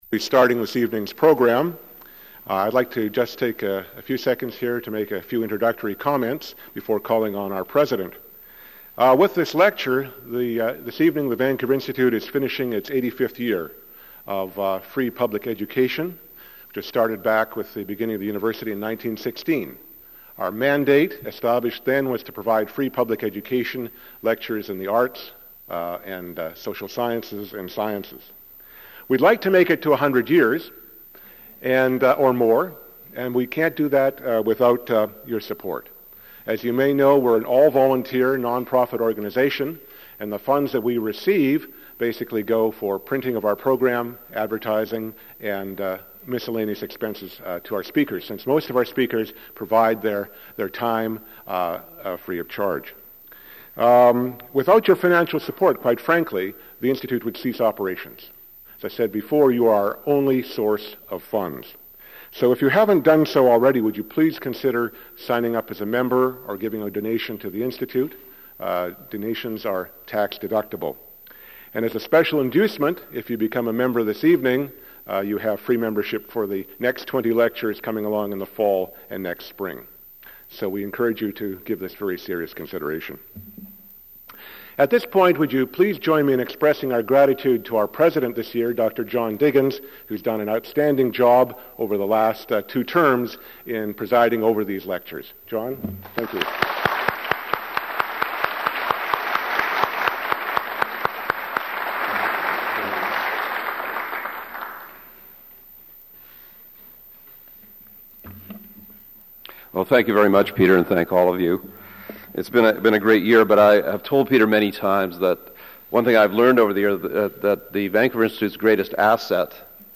Item consists of a digitized copy of an audio recording of a Vancouver Institute lecture